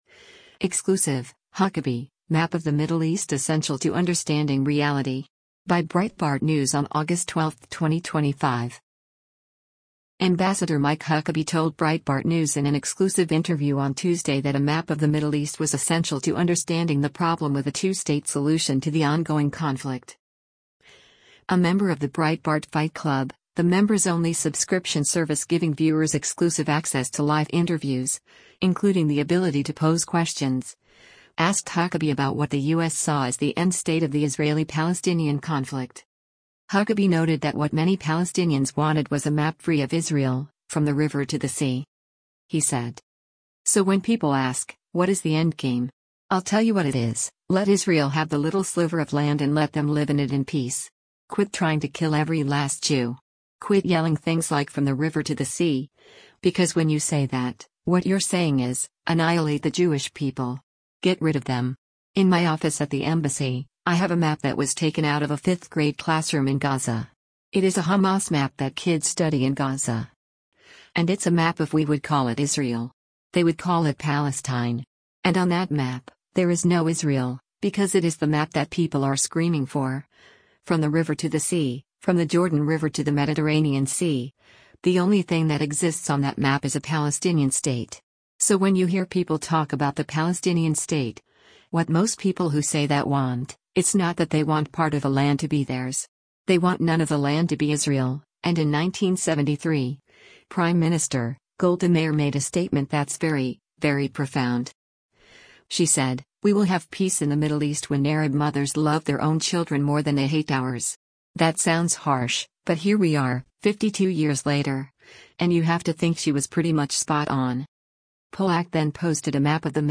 Ambassador Mike Huckabee told Breitbart News in an exclusive interview on Tuesday that a map of the Middle East was essential to understanding the problem with a “two-state solution” to the ongoing conflict.